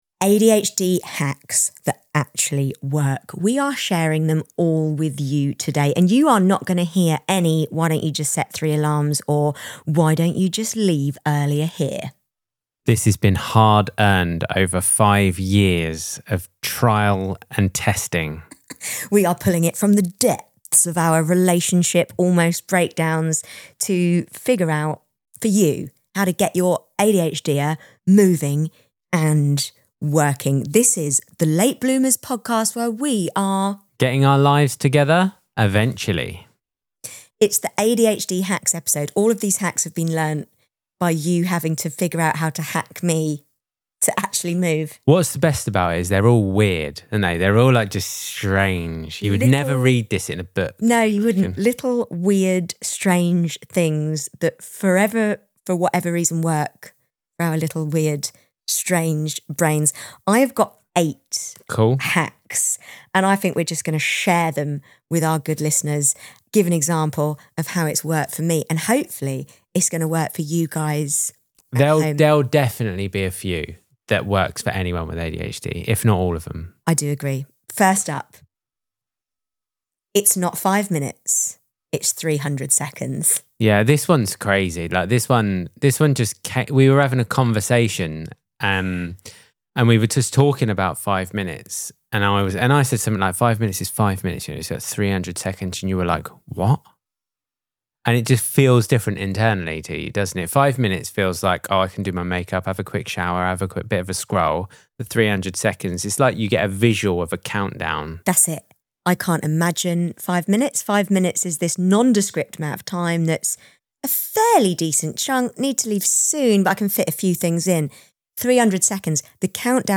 Expect honesty, laughter, and 8 practical tools to try today.